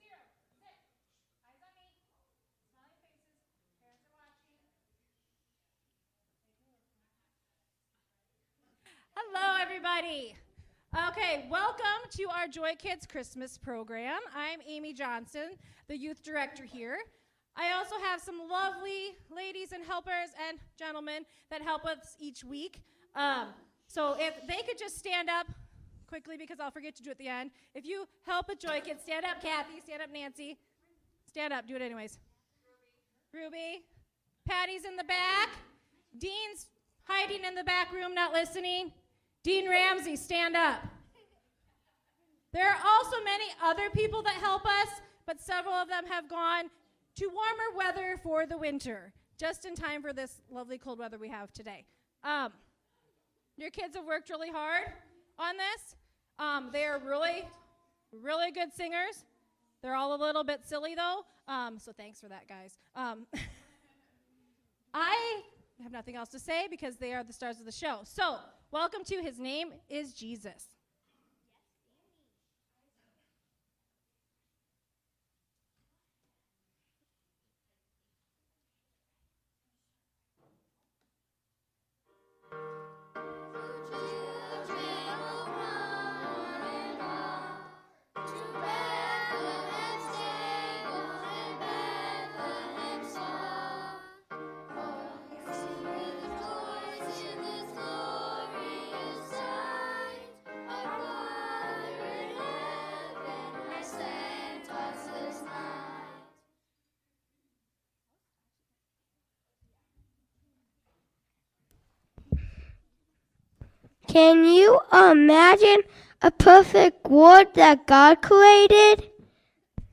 His Name is Jesus–Kids Christmas Program 2024
JoyKids-Christmas-Program-2024-Voice-Only1.mp3